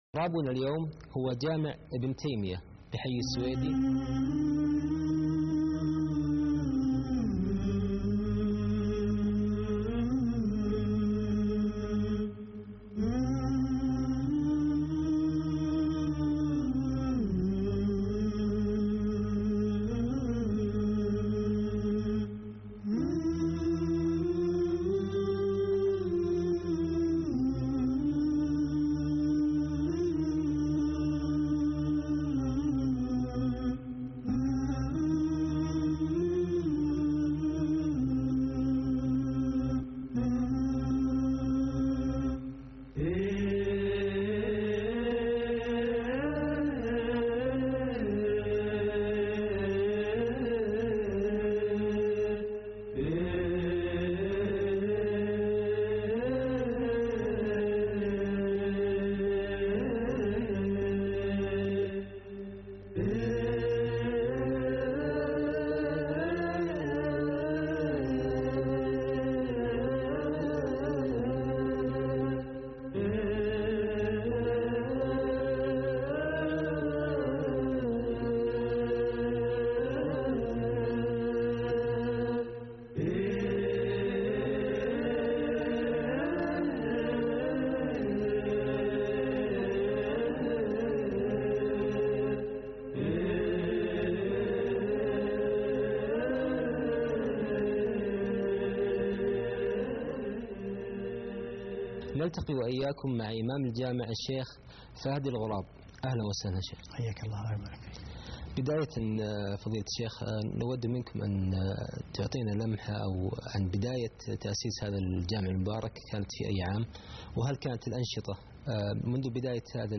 مسجد ابن تيمية بالرياض